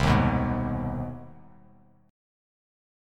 CmM7#5 chord